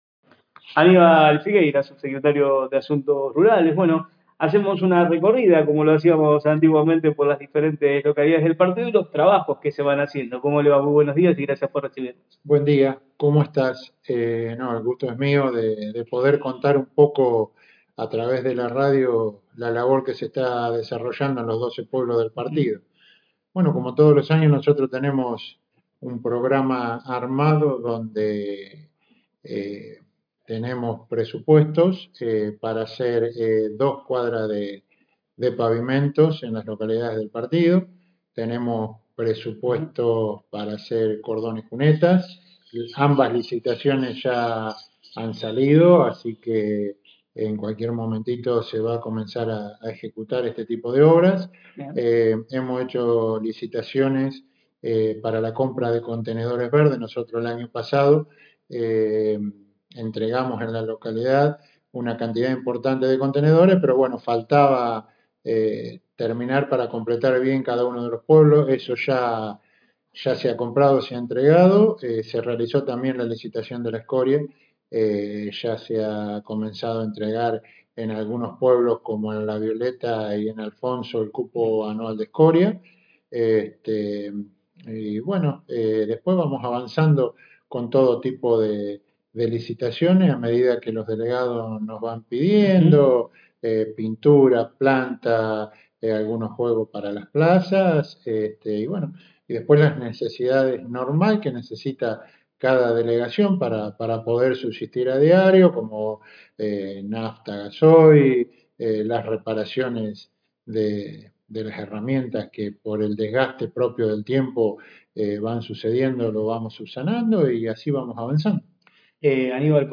En el marco de una recorrida por las localidades del partido, el móvil de LT35 Radio Mon AM 1540 dialogó con Aníbal Figueiras, quien brindó detalles sobre los trabajos que se vienen realizando en los pueblos y las proyecciones para este 2026.